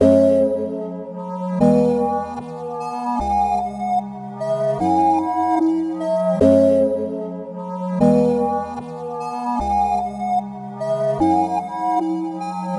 描述：视频游戏反转的旋律
Tag: 150 bpm Hip Hop Loops Synth Loops 2.15 MB wav Key : Am FL Studio